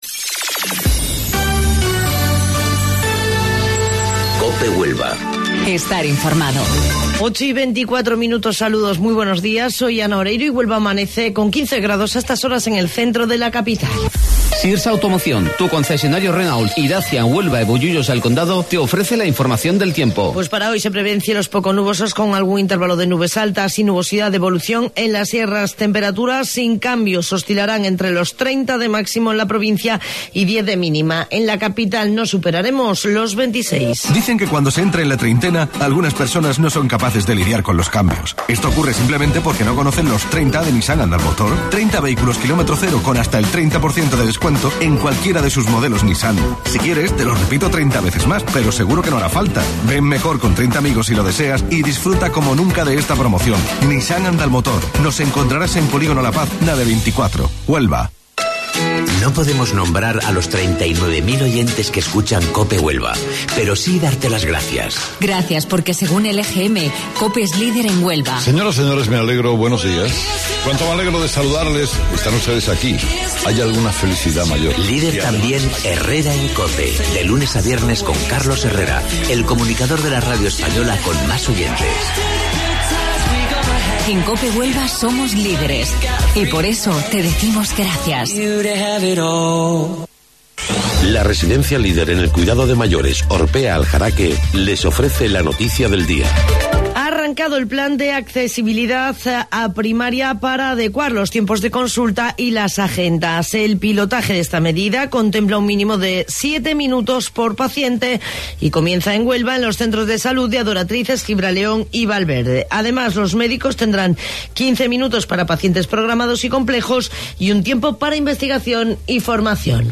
AUDIO: Informativo Local 08:25 del 30 de Abril